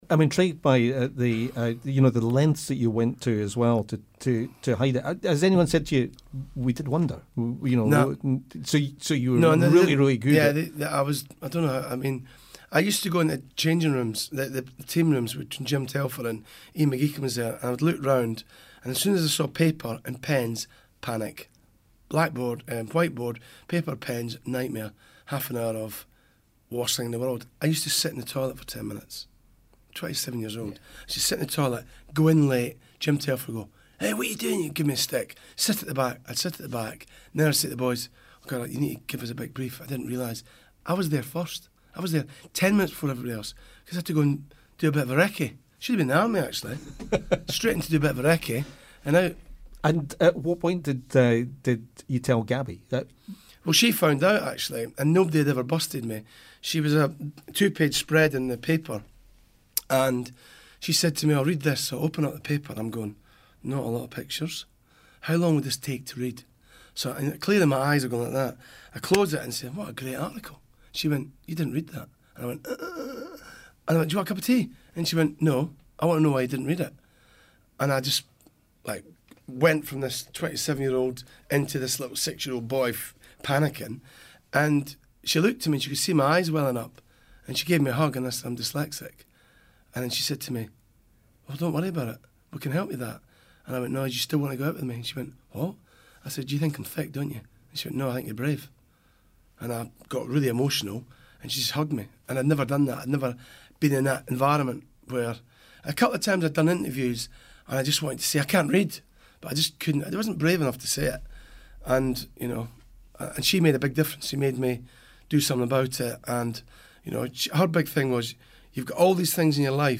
a special St Andrews Day edition of the BFBS Scotland Breakfast Show...